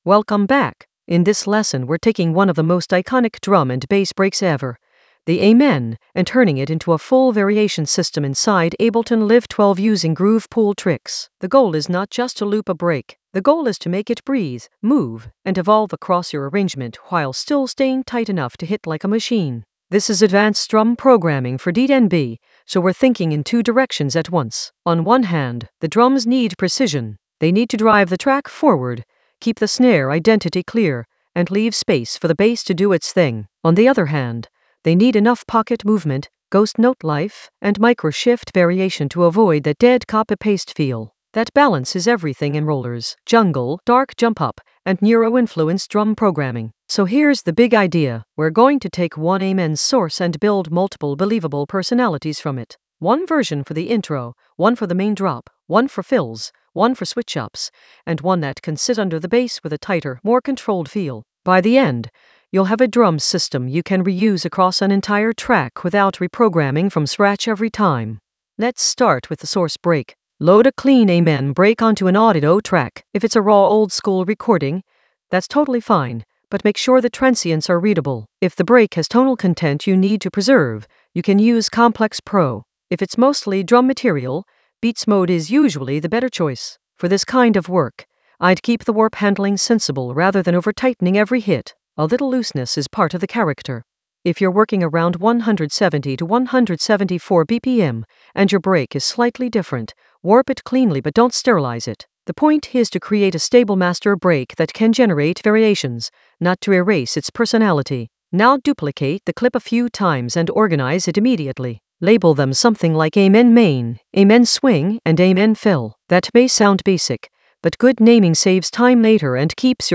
Narrated lesson audio
The voice track includes the tutorial plus extra teacher commentary.
An AI-generated advanced Ableton lesson focused on Course for amen variation using groove pool tricks in Ableton Live 12 in the Drums area of drum and bass production.